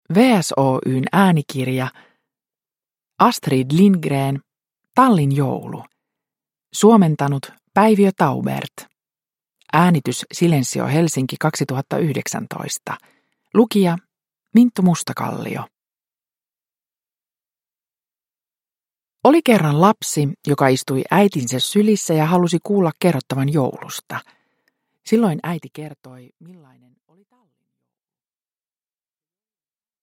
Tallin joulu – Ljudbok – Laddas ner